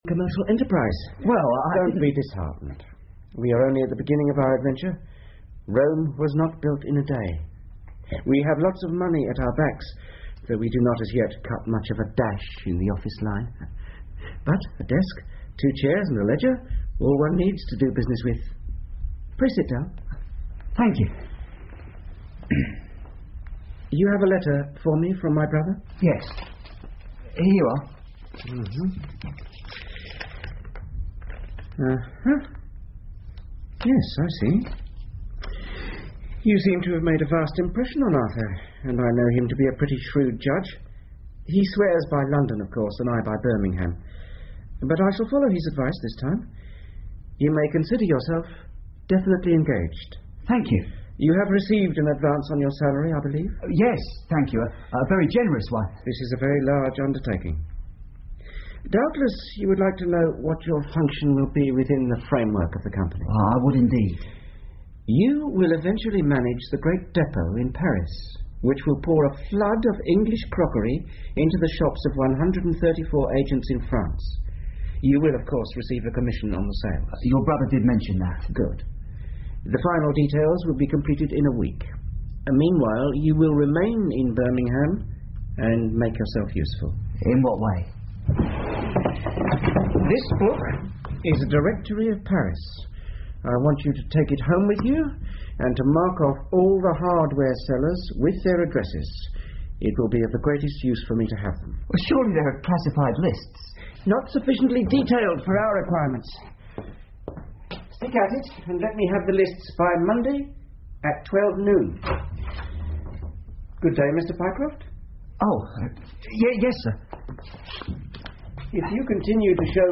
福尔摩斯广播剧 The Stock Brokers Clerk 5 听力文件下载—在线英语听力室